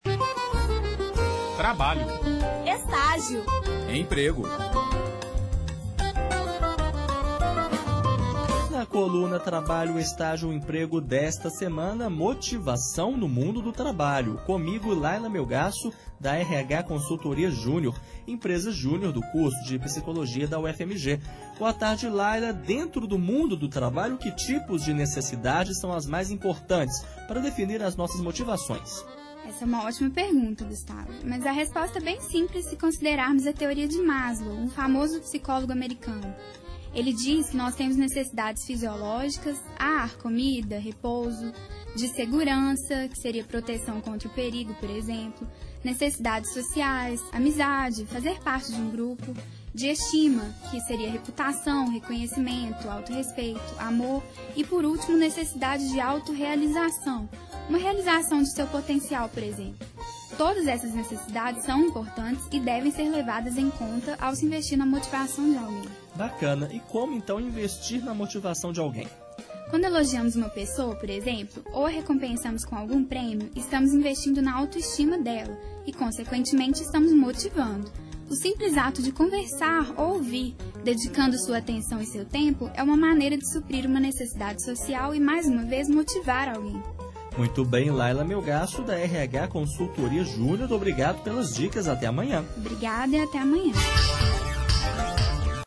Entrevista concedida ao jornalista